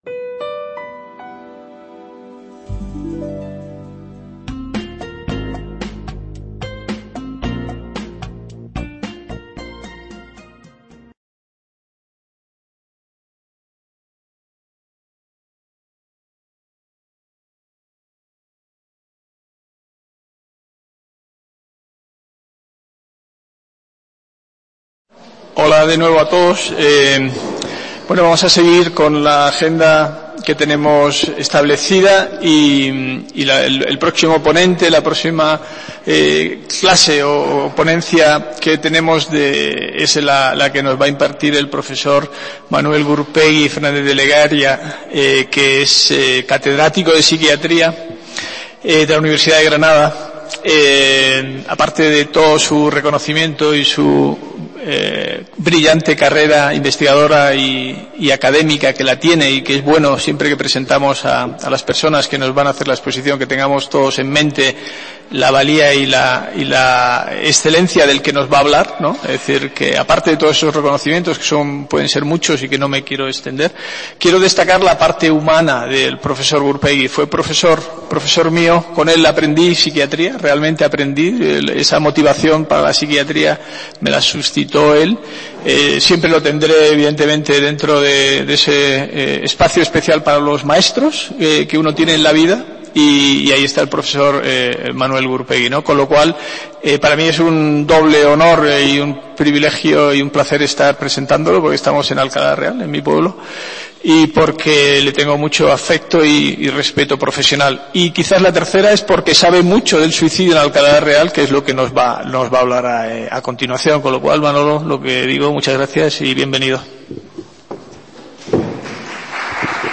ponencia
Cursos de Verano de la UNED